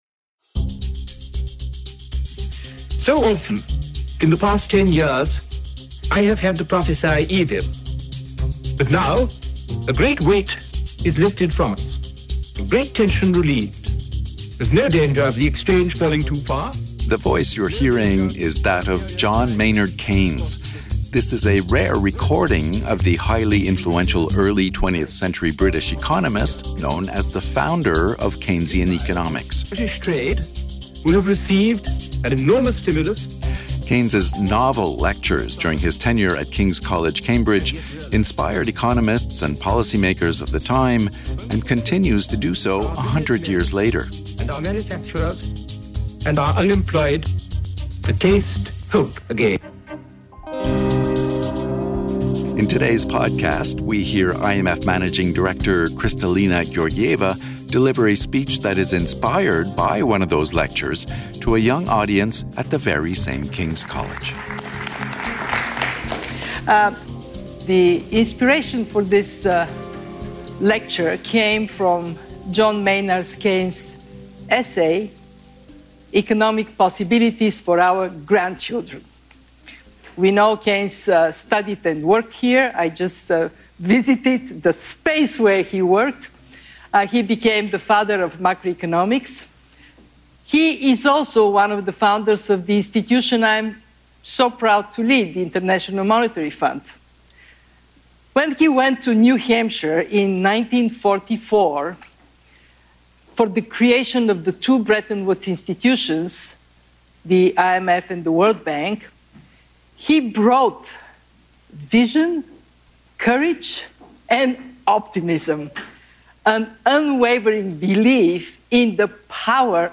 In this podcast, IMF Managing Director Kristalina Georgieva delivers a speech inspired by one of Keynes’ lectures to a young audience at the very same King’s College.
md-cambridge-speech-mix1.mp3